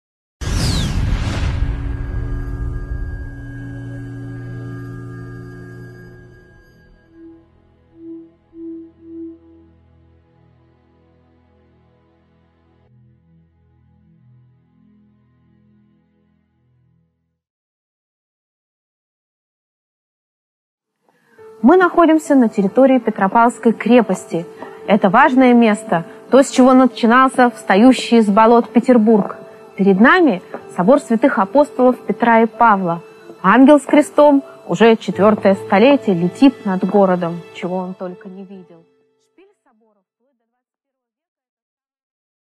Аудиокнига Юный град. Основание Санкт-Петербурга и его идея. Эпизод 4 | Библиотека аудиокниг